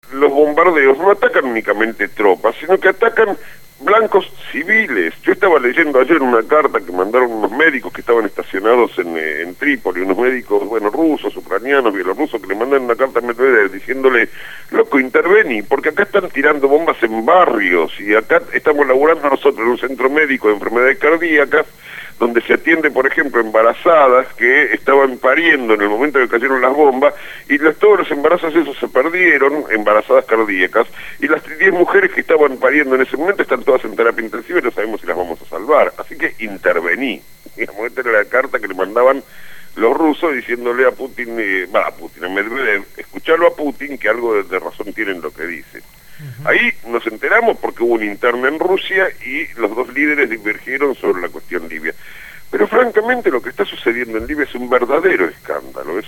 analista internacional, analiza los sucesos en Libia en el programa «Desde el barrio» (Lunes a viernes de 9 a 12 horas) por Radio Gráfica.